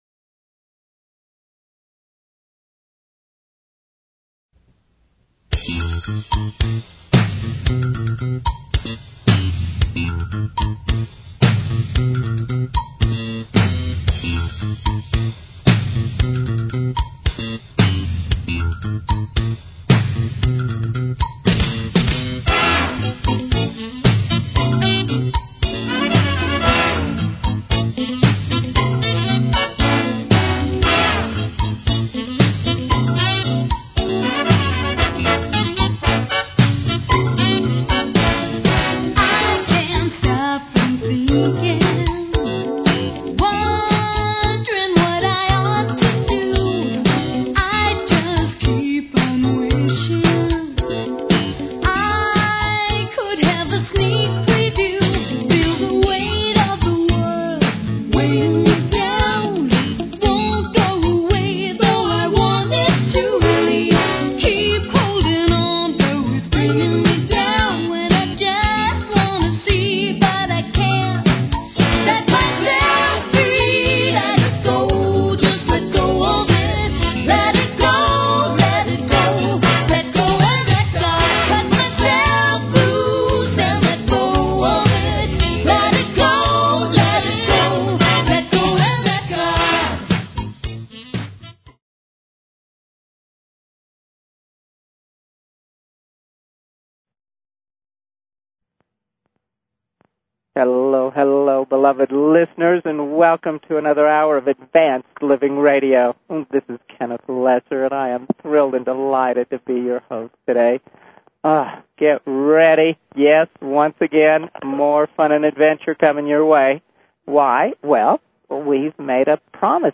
Talk Show Episode, Audio Podcast, Advanced_Living and Courtesy of BBS Radio on , show guests , about , categorized as